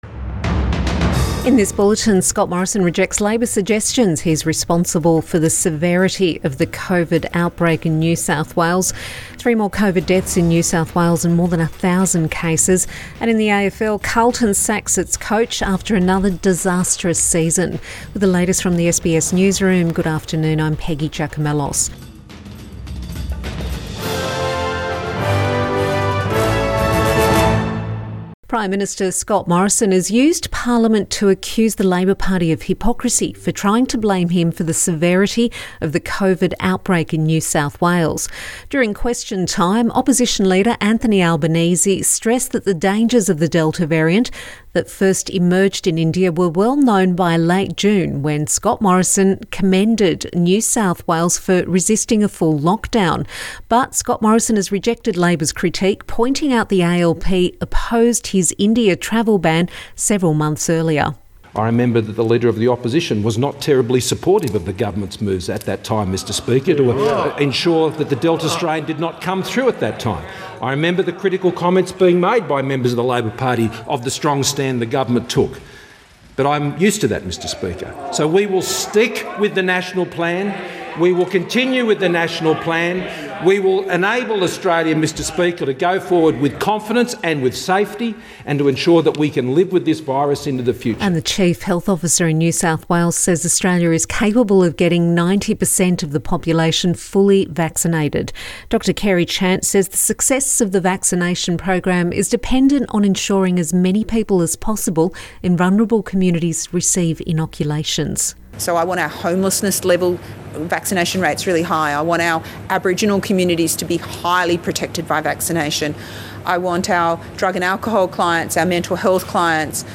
PM bulletin 26 August 2021